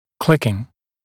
[‘klɪkɪŋ][‘кликин]щелчки, пощелкивание